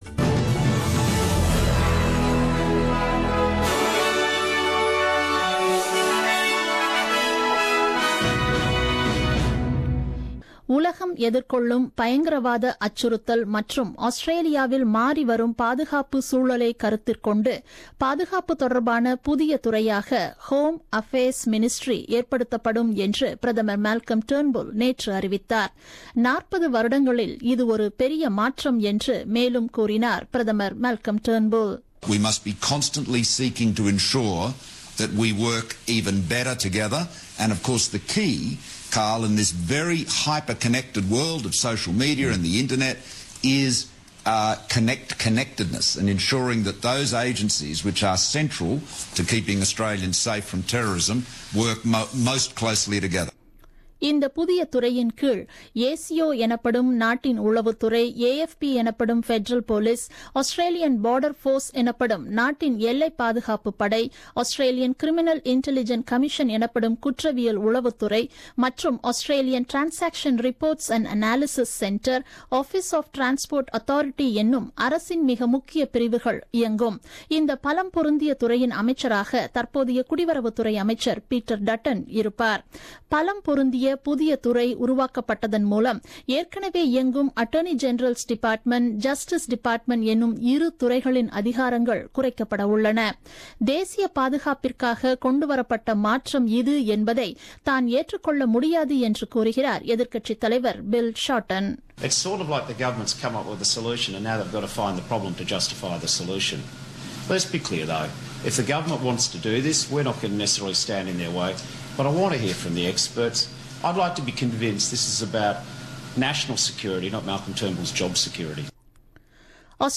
The news bulletin broadcasted on 19th July 2017 at 8pm.